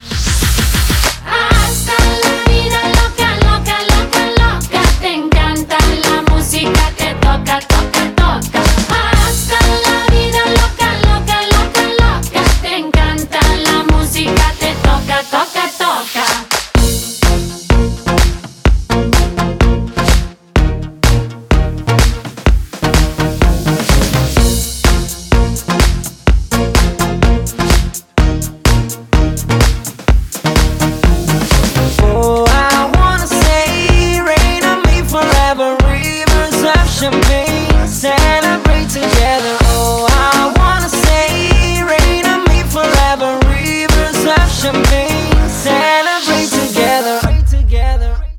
• Качество: 128, Stereo
зажигательные
заводные
house
Eurodance